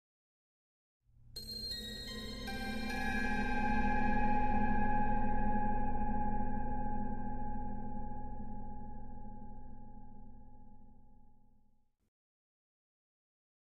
High Strings
Harmonic Plucks Harmonic Plucks Mystic 5 Note Arpeggio 1